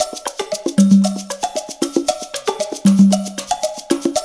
CONGA BEAT28.wav